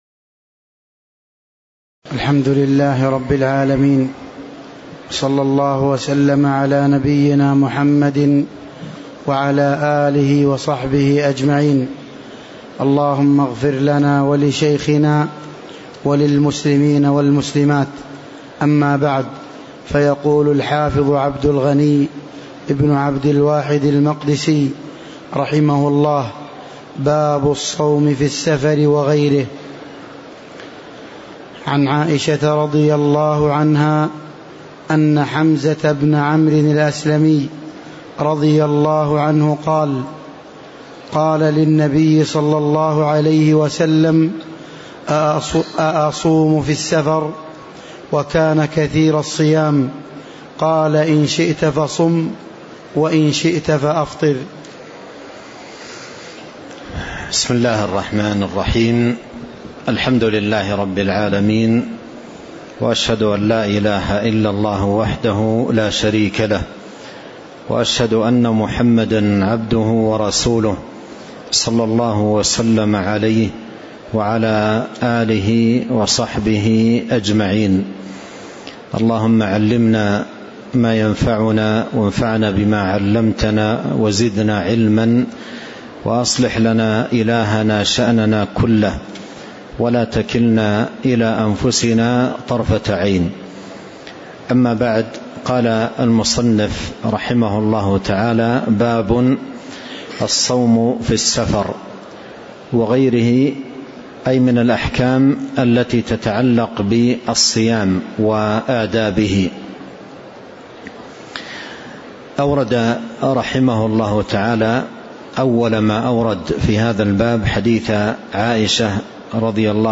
تاريخ النشر ٣ جمادى الآخرة ١٤٤٤ هـ المكان: المسجد النبوي الشيخ